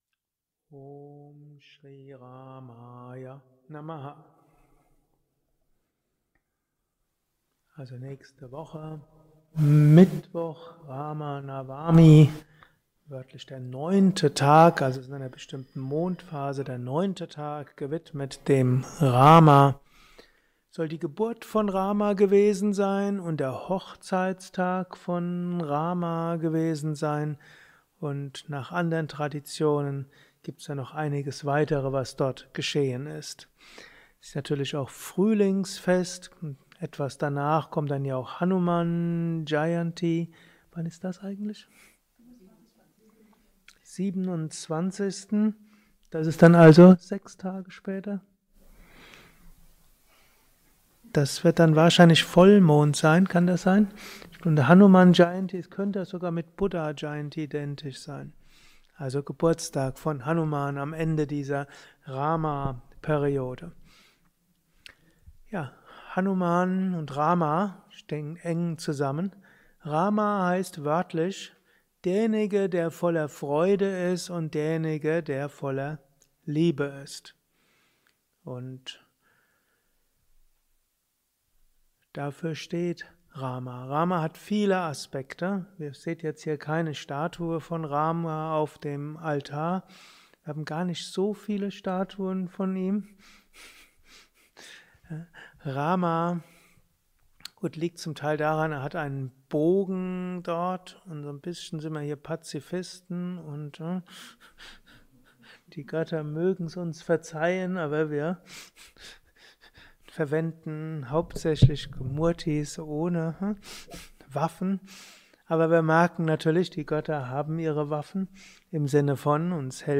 eine Aufnahme während eines Satsangs
gehalten nach einer Meditation im Yoga Vidya Ashram Bad